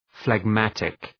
Προφορά
{fleg’mætık}